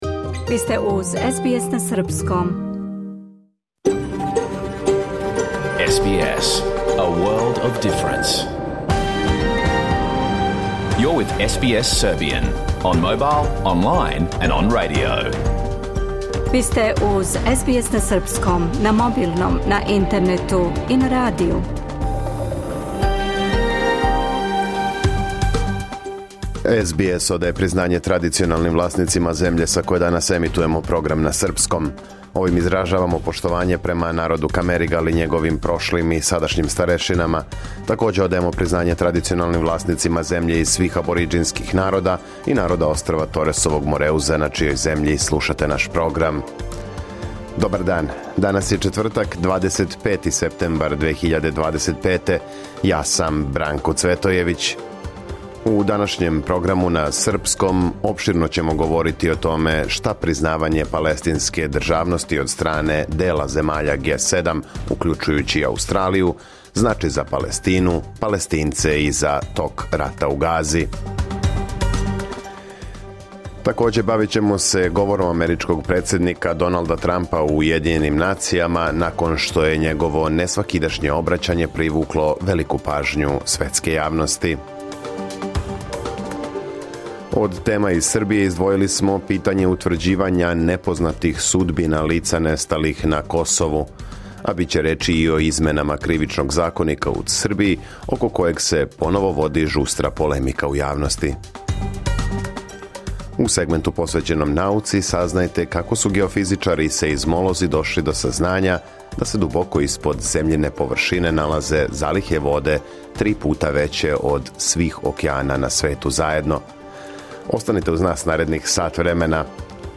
Програм емитован уживо 25. септембра 2025. године